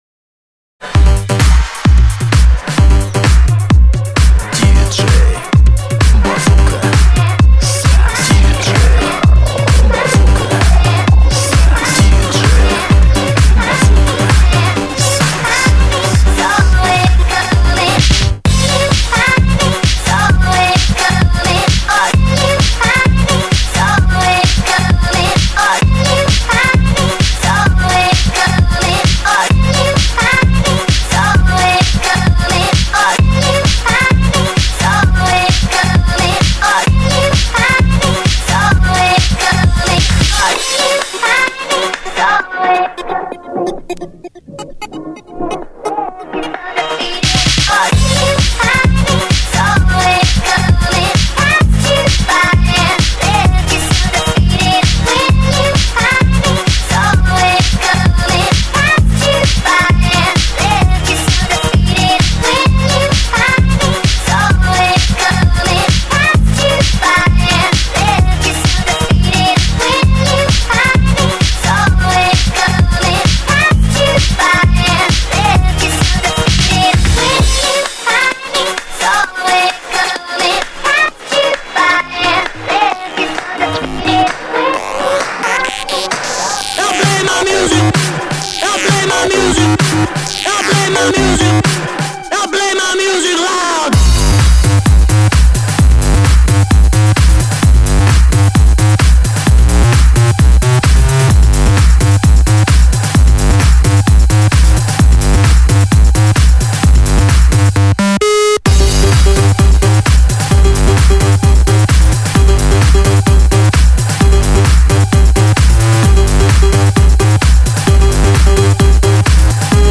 DNB
миксовка... стоит послушать))) начало всем известный хит...